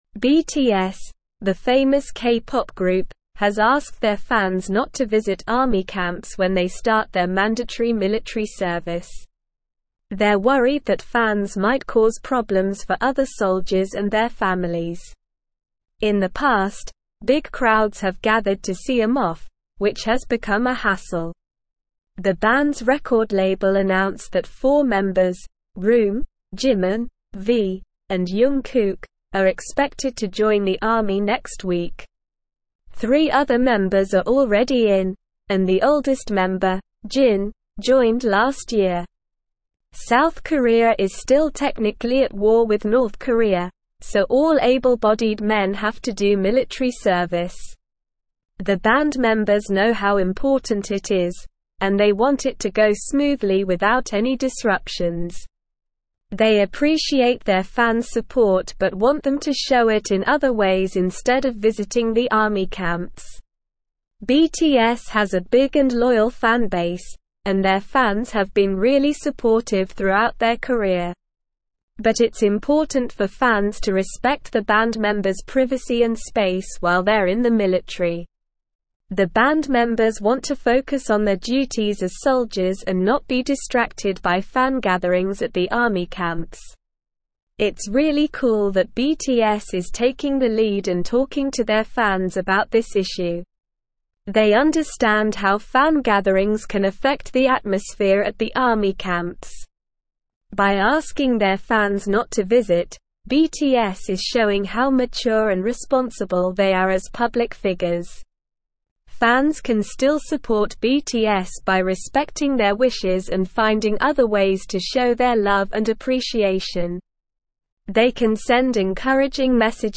Slow
English-Newsroom-Upper-Intermediate-SLOW-Reading-BTS-Urges-Fans-to-Stay-Away-from-Army-Camps.mp3